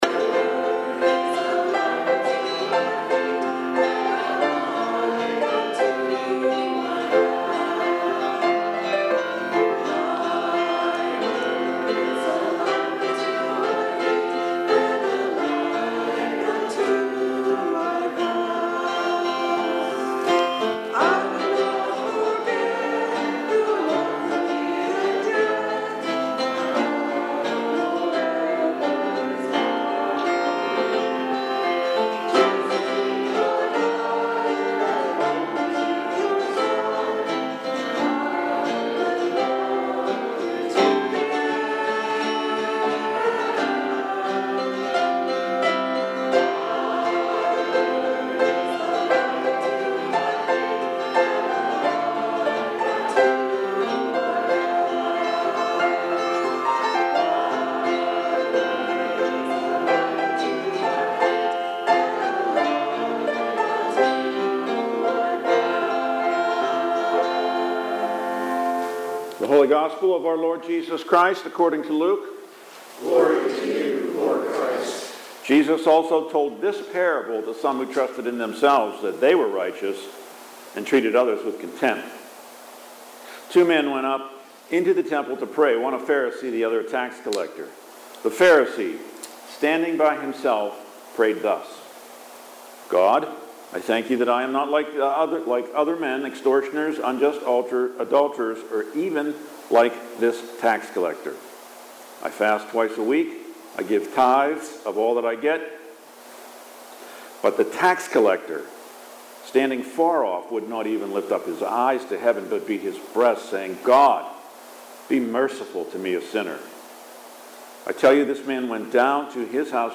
Readings and Sermon for October 26 – Saint Alban's Anglican Church